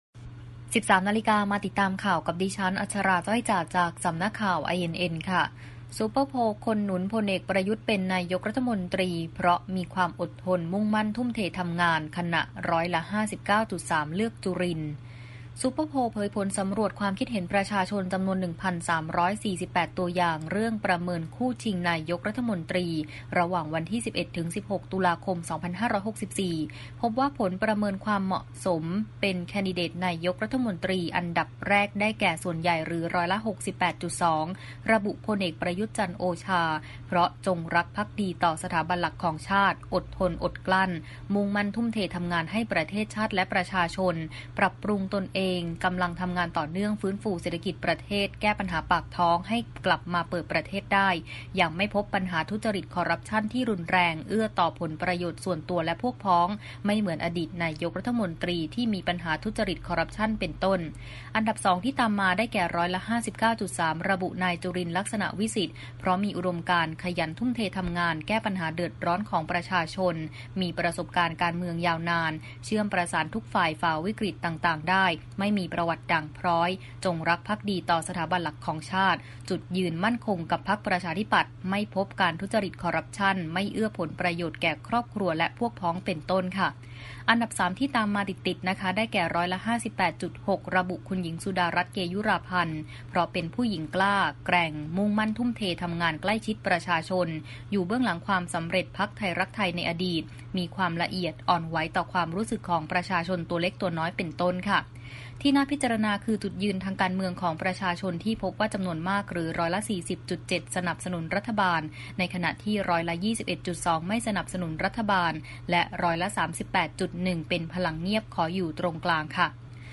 คลิปข่าวต้นชั่วโมง
ข่าวต้นชั่วโมง 13.00 น.